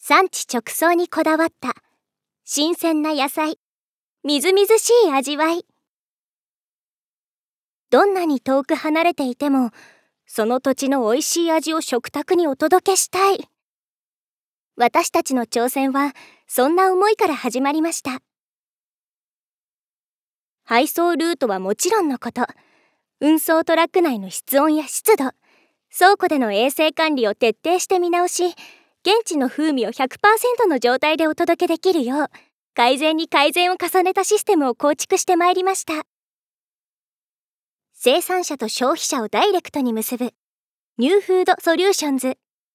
ナレーション1真物.mp3